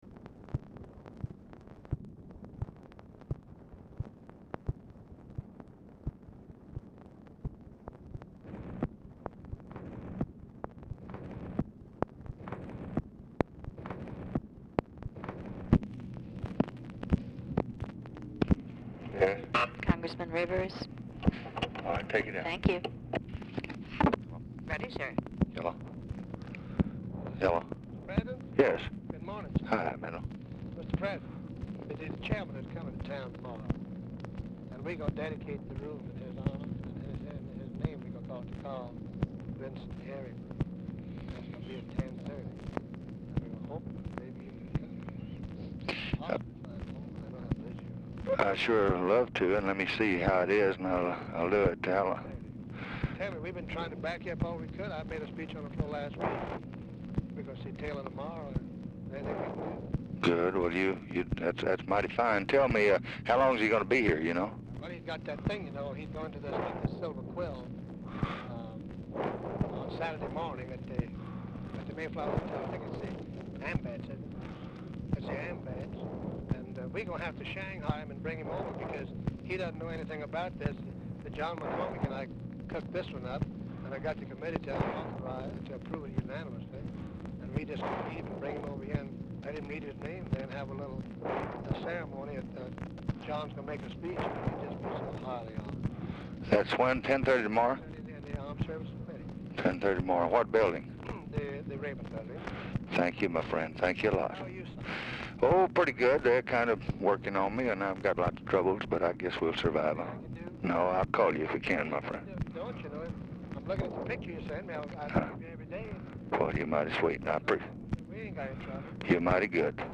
Telephone conversation # 7307, sound recording, LBJ and MENDEL RIVERS, 4/1/1965, 10:10AM | Discover LBJ
Format Dictation belt
Location Of Speaker 1 Oval Office or unknown location
Specific Item Type Telephone conversation